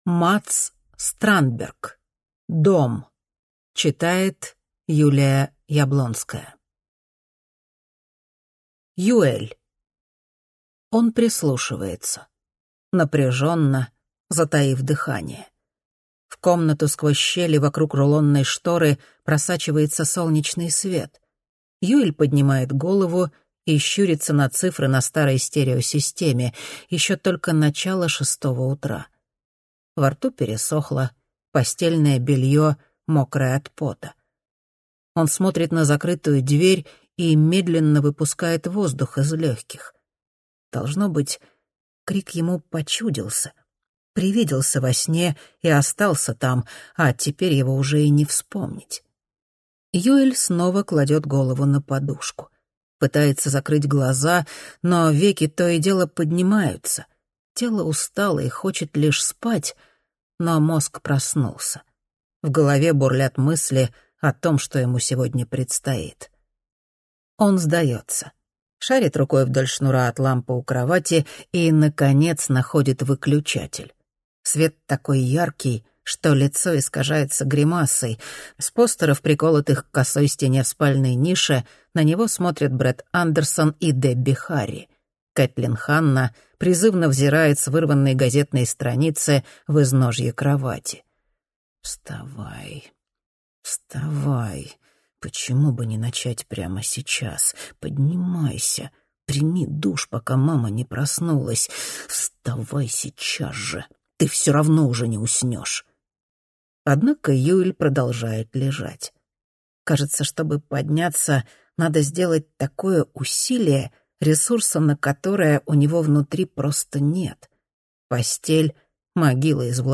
Аудиокнига Дом | Библиотека аудиокниг